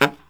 LOHITSAX06-R.wav